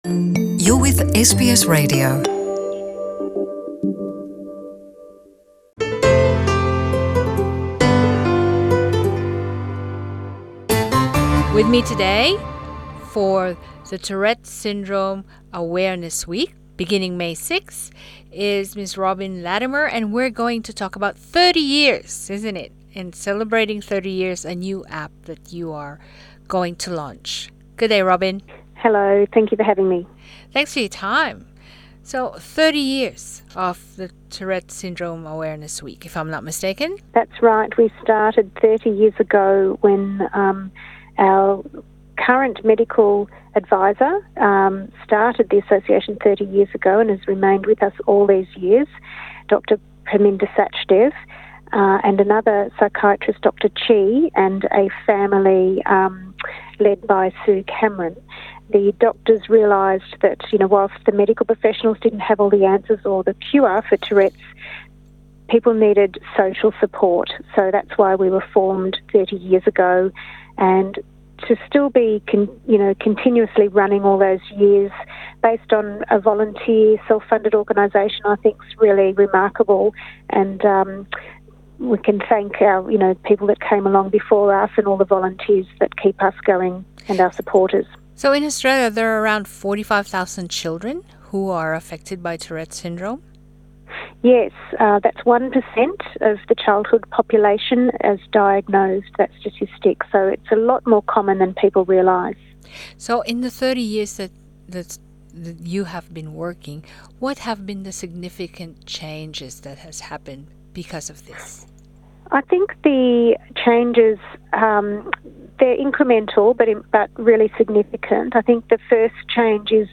Walang lunas sa kondisyon na Tourette Syndrome. Ang pag unawa at alis sa stigma kakabit nito ay mahalaga upang matulungan ang mga nabubuhay ng may Tourette na mamuhay ng normal. Narito ang panayam